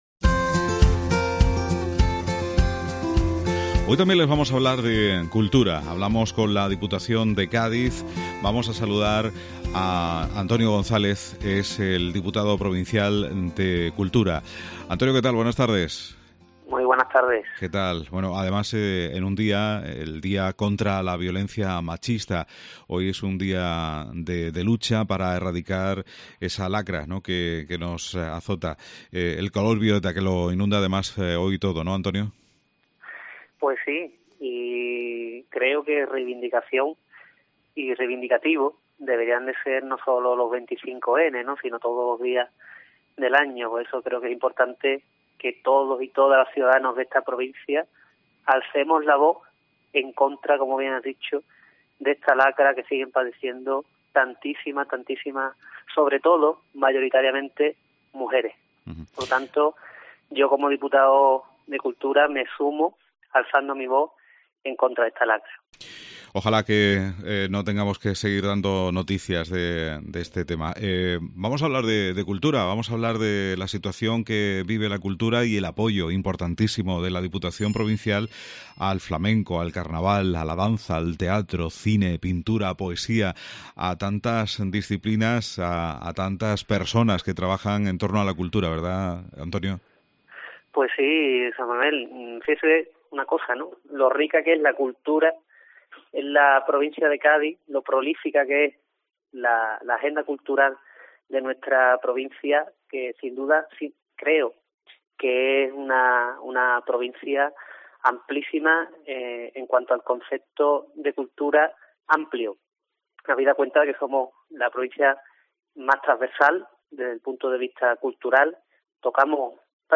Antonio González, Diputado provincial de Cultura